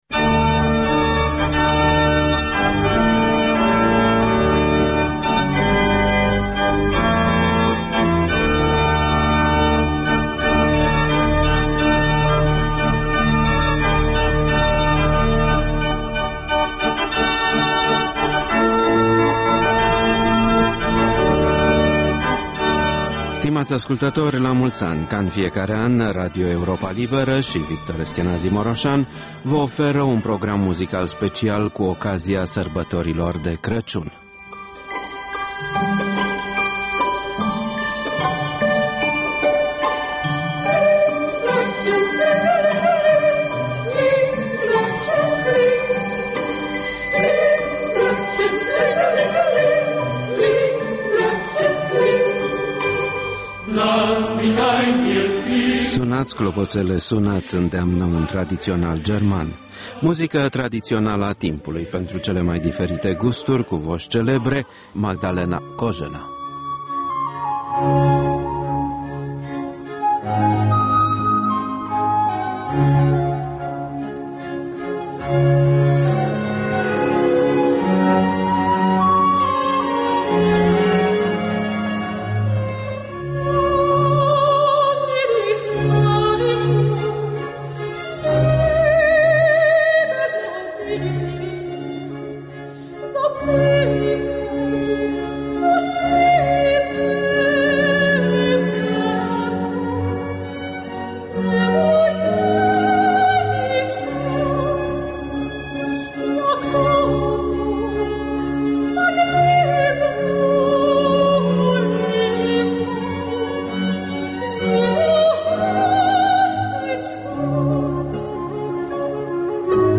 Un program muzical special cu ocazia Crăciunului